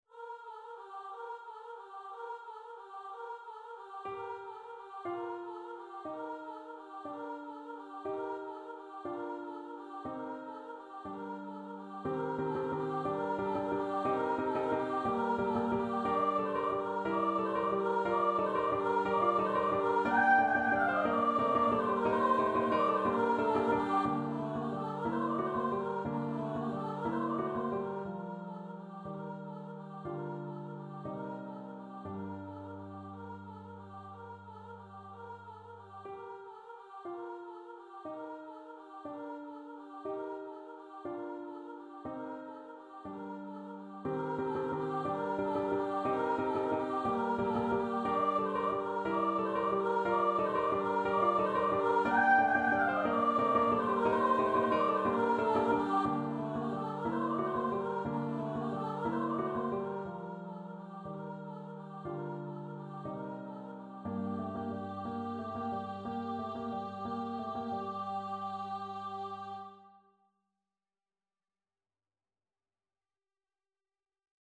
Free Sheet music for Choir (SATB)
3/4 (View more 3/4 Music)